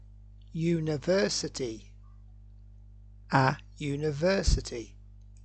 (you-ni-ver-sity. Vowel sound so a.)
you-ni-ver-sity.mp3